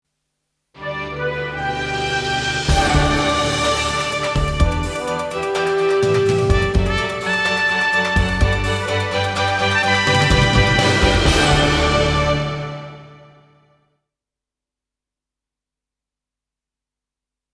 opening titles